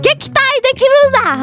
Worms speechbanks
Goaway.wav